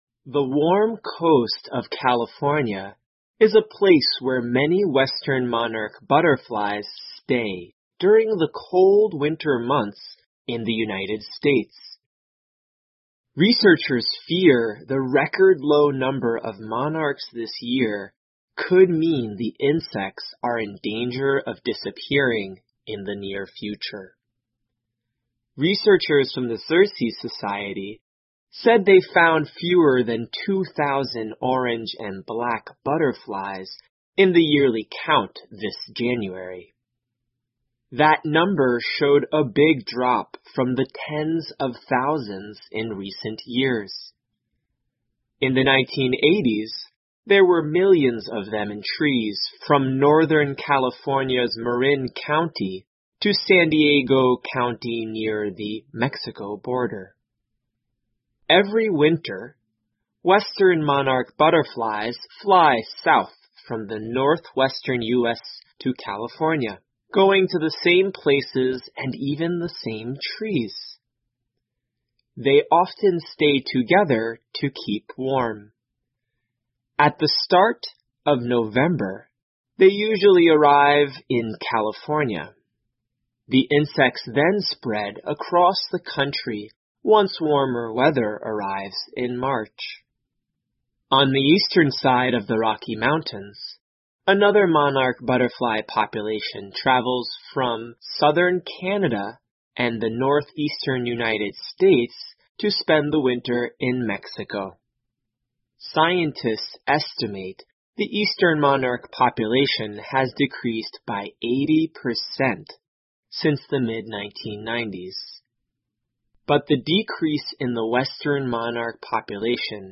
VOA慢速英语2020--帝王蝶种群即将灭绝 听力文件下载—在线英语听力室